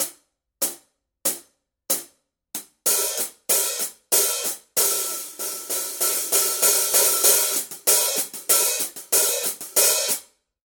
Paiste 14" PST 8 Reflector Medium Hi-Hats Cymbal | Nicko's Drum One